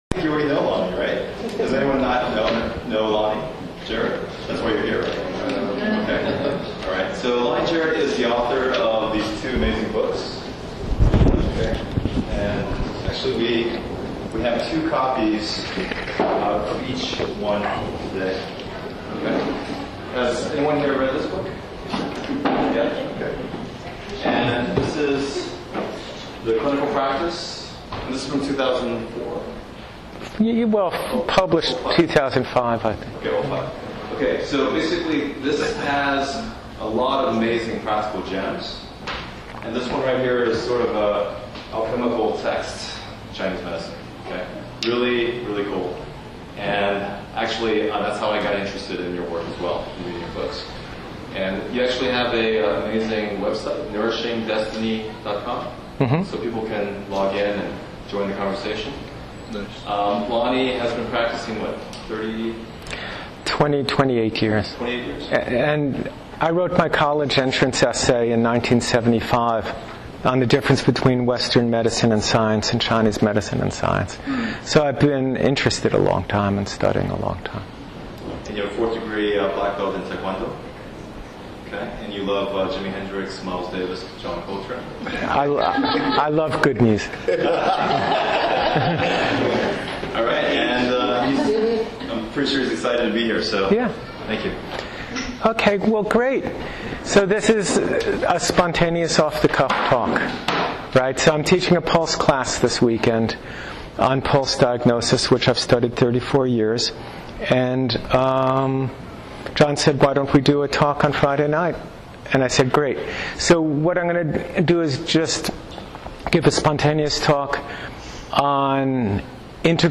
Integral, Evolutionary, and Spiritual Perspectives on the Practice of Chinese Medicine. Given at Teance tea house in Berkeley, California, 5.17.13.